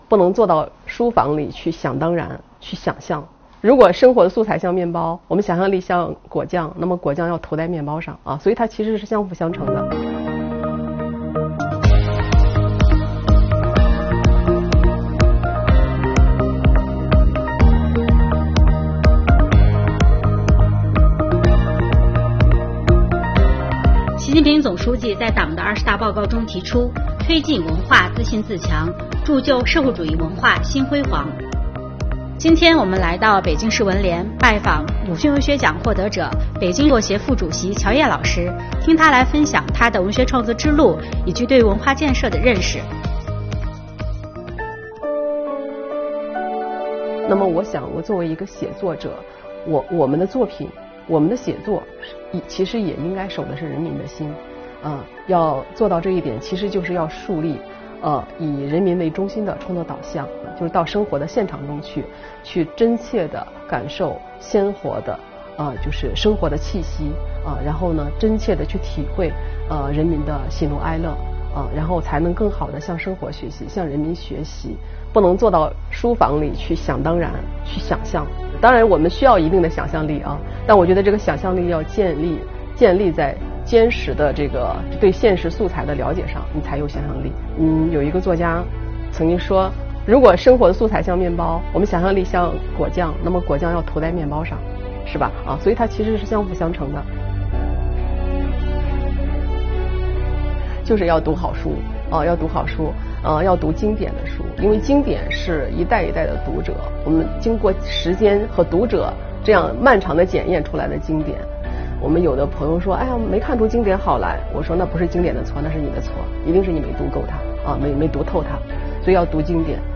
近日，本报记者专访北京作协副主席、鲁迅文学奖获得者乔叶，请她分享她的文学创作之路、她对作家与时代的关系的认识，以及对税收文化的理解。